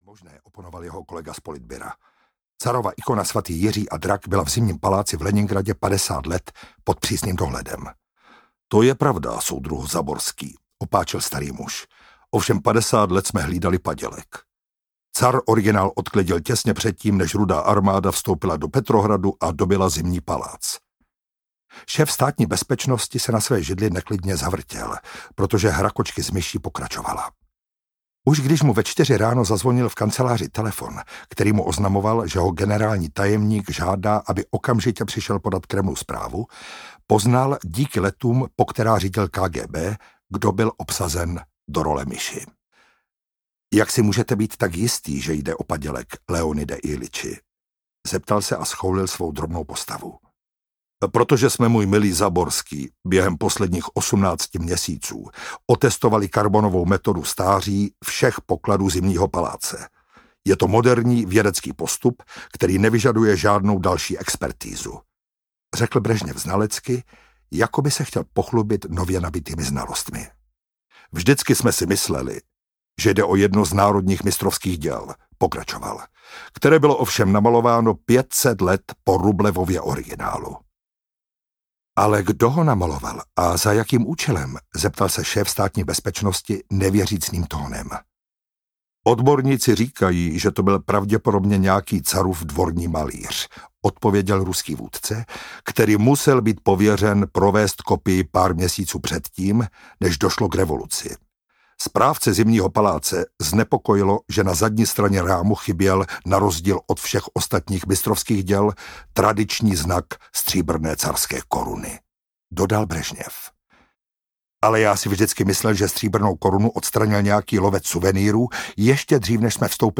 Věc cti audiokniha
Ukázka z knihy
• InterpretMiroslav Etzler